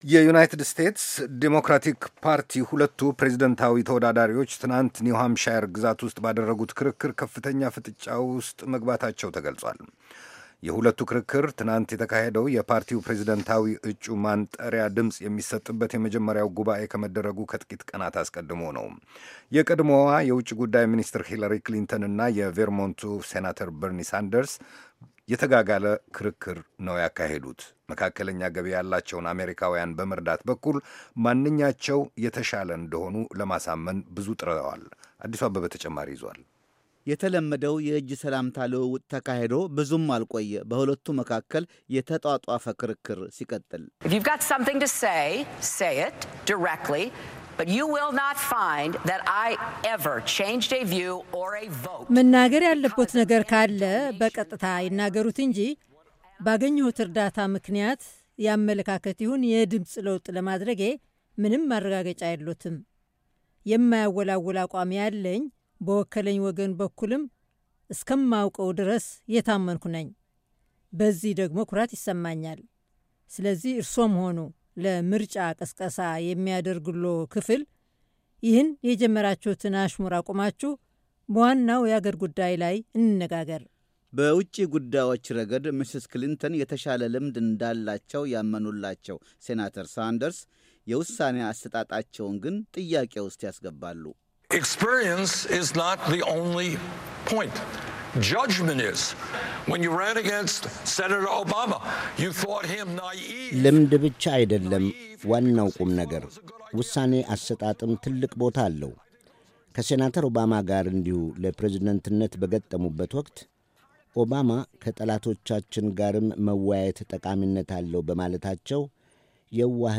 ሁለቱ ዲሞክራቶች፣ በምርጫ ዘመቻው ተራማጅ ሃሳብ ያለው ማነው በሚለው ነጥብ ዙሪያም ተከራክረዋል። ከኒው ሃምፕሸሩ ክርክር በፊት በተካሄደ የሕዝብ አስተያየት መለኪያ መሠረት፣ ሂለሪ ክሊንተን፣ ከሴናተር ሳንደርስ በ16 ነጥብ ወደ ኋላ እንደነበሩም በመርዳት ረገድ ማንኛቸው የተሻሉ እንደሆኑ ለማሳመን ባደረጉት ጥረት ነው። ዘገባውን ለማዳመት የድምጽ ፋይሉን ይጫኑ።